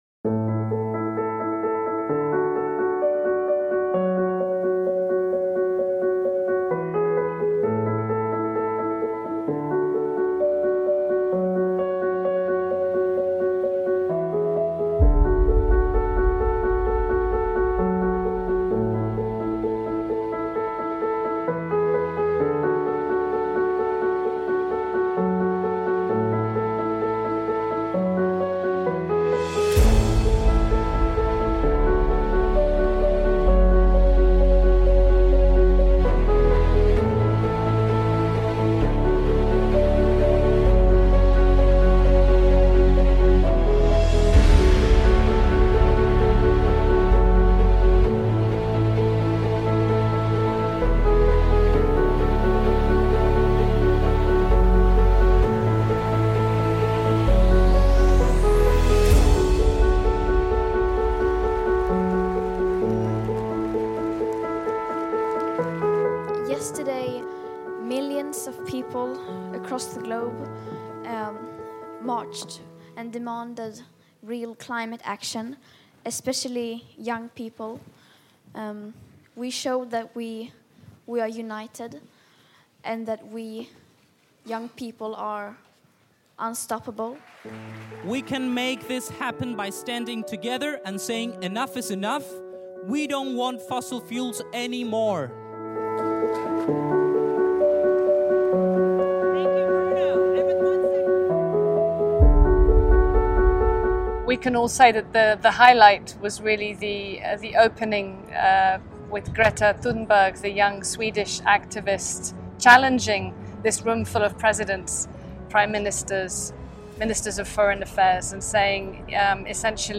Candid conversation in New York, on the sidelines of the UN General Assembly Annual Session.